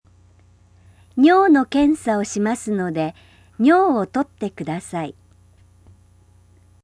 Contoh Kalimat
dari staf kepada pasien/klien